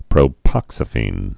(prō-pŏksə-fēn)